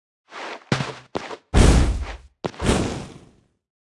Media:Sfx_Anim_Classic_Wizard.wavMedia:Sfx_Anim_Super_Wizard.wavMedia:Sfx_Anim_Ultra_Wizard.wav 动作音效 anim 在广场点击初级、经典、高手和顶尖形态或者查看其技能时触发动作的音效
Sfx_Anim_Baby_Wizard.wav